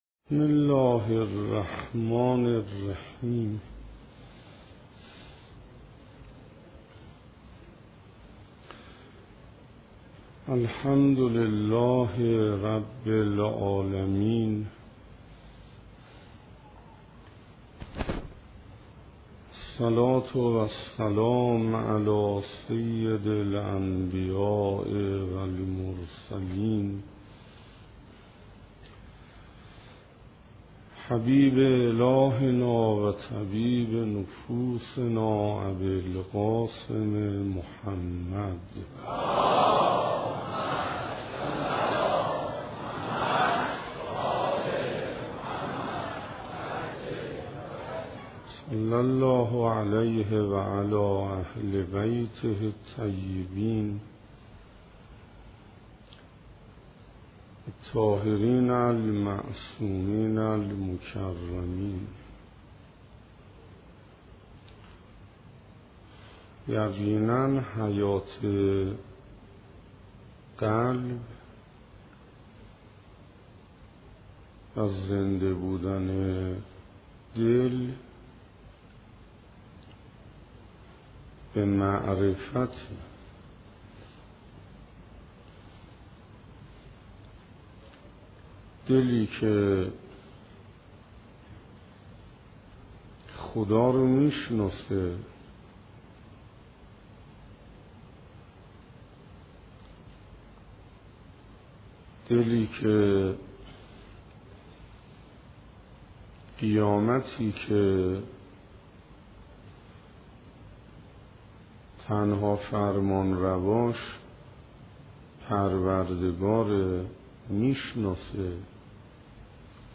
دانلود دوازدهمین جلسه از مباحث «نور معرفت» در کلام حجت الاسلام استاد حسین انصاریان
سخنرانی